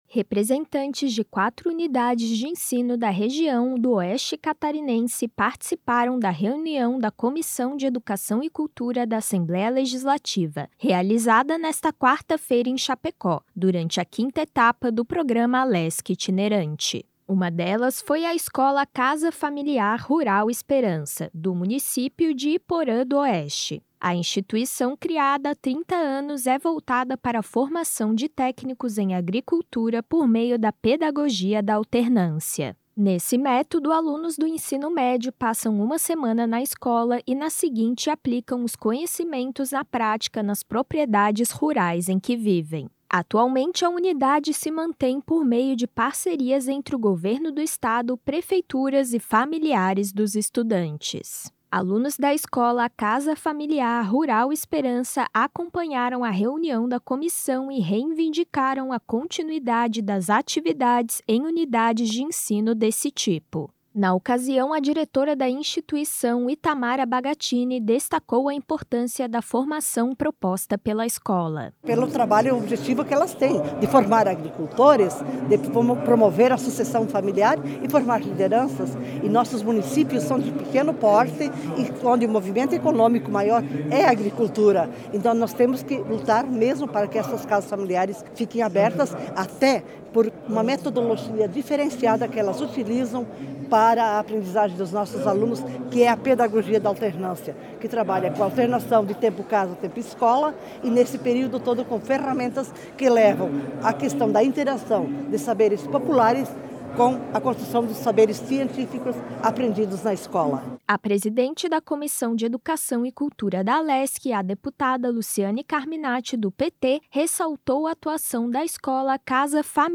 Entrevistas com:
- deputada Luciane Carminatti (PT), presidente da Comissão de Educação e Cultura da Assembleia Legislativa.